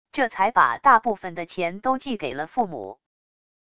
这才把大部分的钱都寄给了父母wav音频生成系统WAV Audio Player